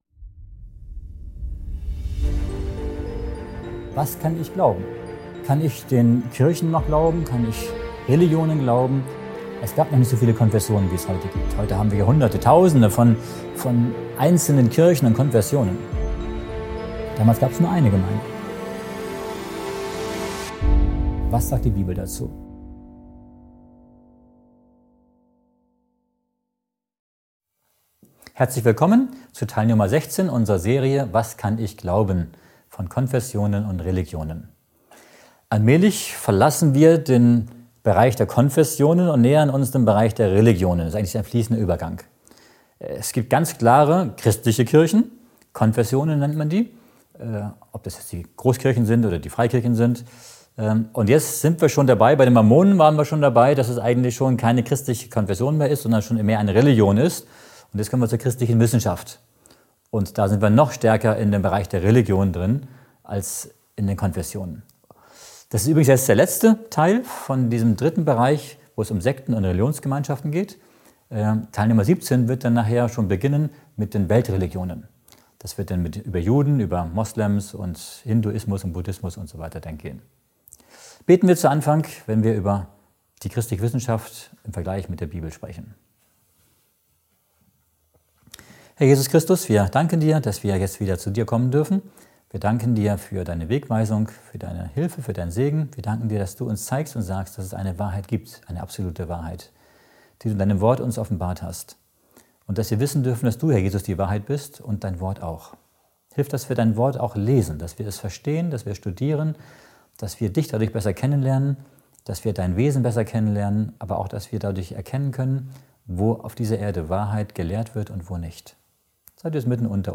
Dieser Vortrag beleuchtet die christliche Wissenschaft und zieht spannende Vergleiche zur Bibel.